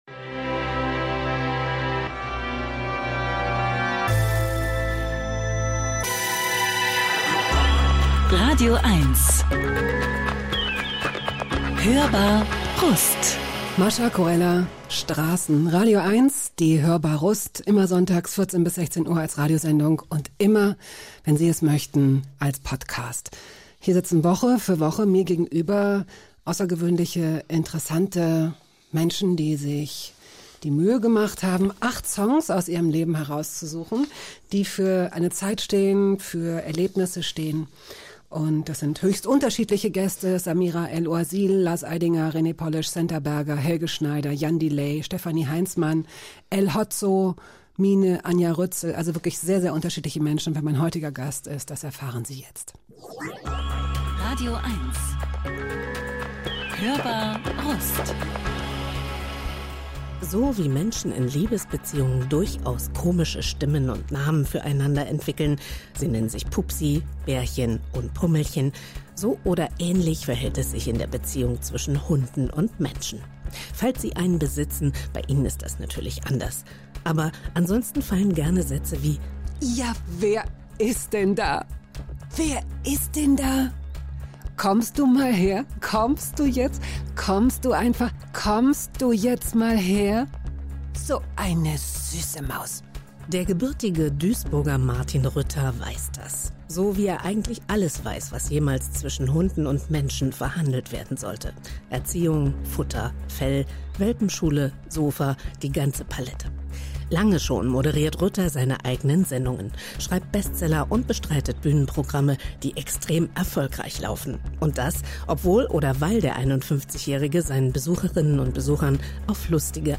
im Gespräch mit Jakob Augstein